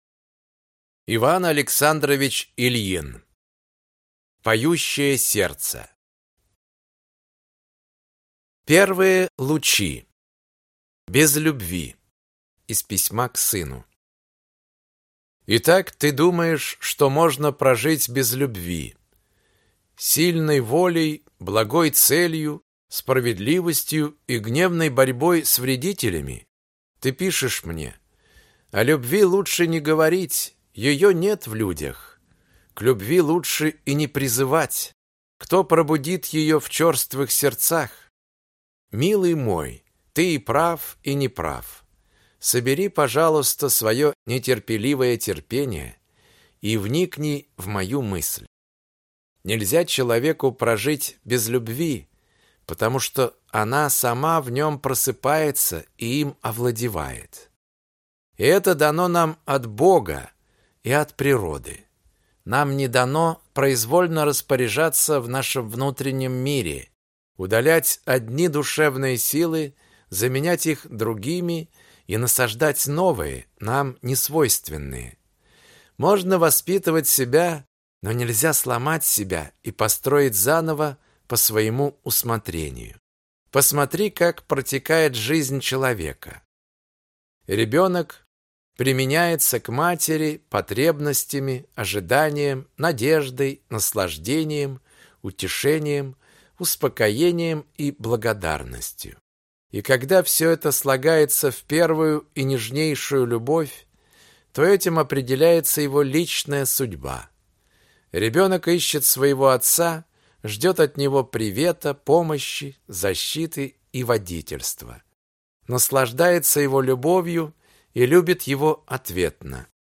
Аудиокнига Поющее сердце. Книга тихих созерцаний | Библиотека аудиокниг